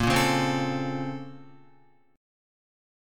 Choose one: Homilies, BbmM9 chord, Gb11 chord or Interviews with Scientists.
BbmM9 chord